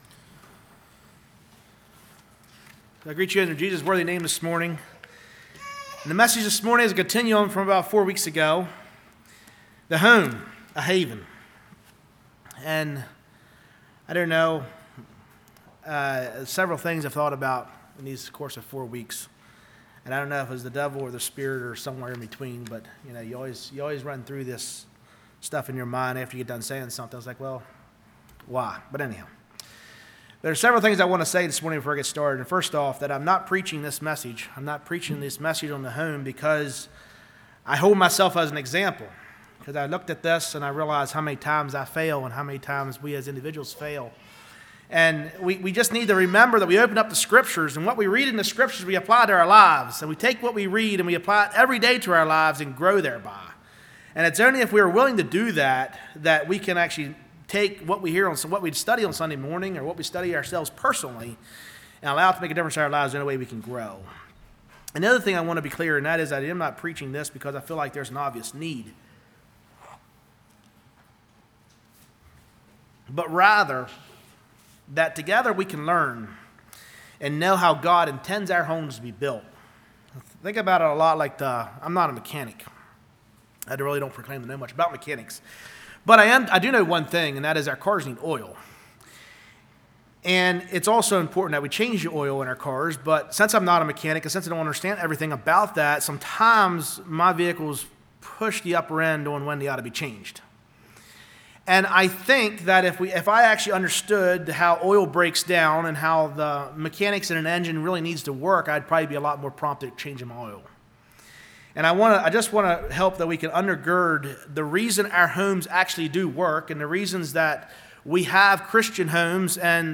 This is a typical all day meeting in one of the churches.